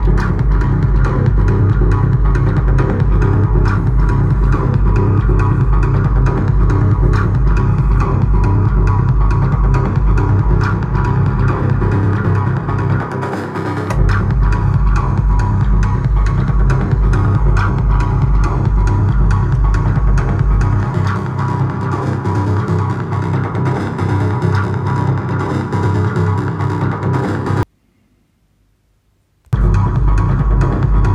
Modular loops :slight_smile: (Loquelic IP via OT)